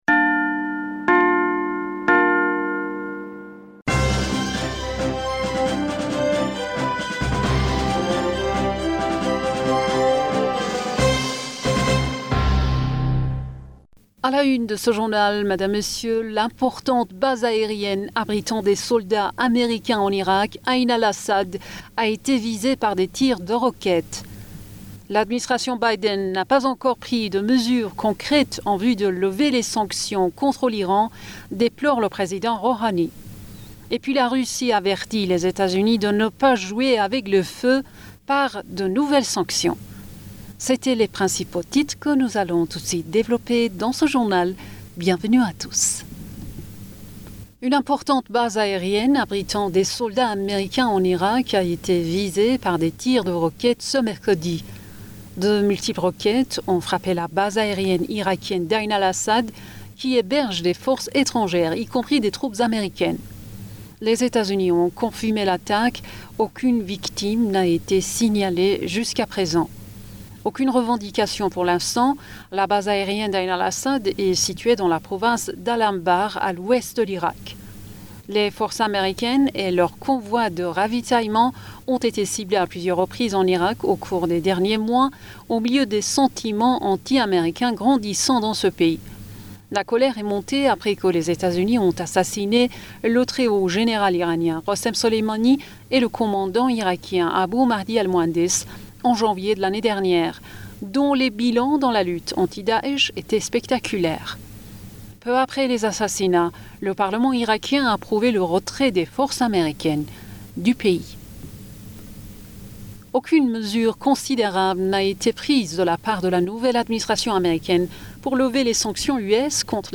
Bulletin d'informationd u 03 Mars 2021